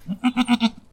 sounds_goat_bleat_03.ogg